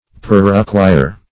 perruquier - definition of perruquier - synonyms, pronunciation, spelling from Free Dictionary Search Result for " perruquier" : The Collaborative International Dictionary of English v.0.48: Perruquier \Per*ru"qui*er\, n. [F.] A maker of perukes or wigs.
perruquier.mp3